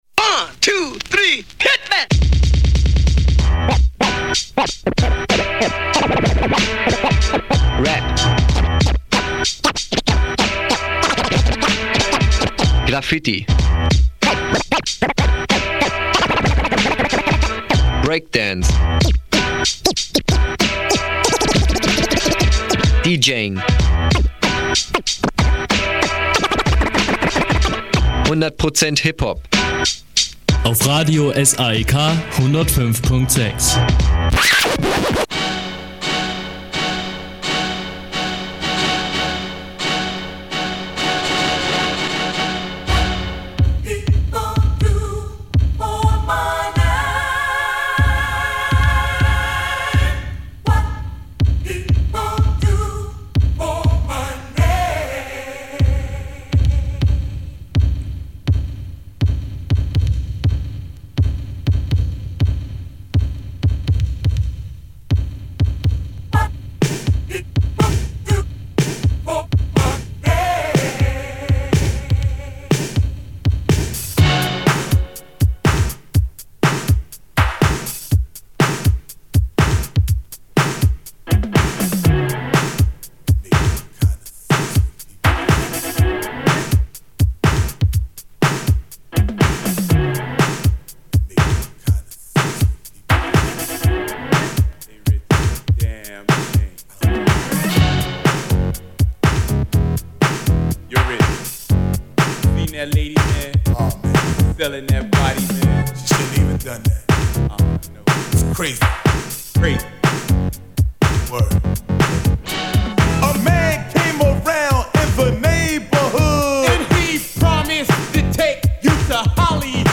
Old School Electro Mix Special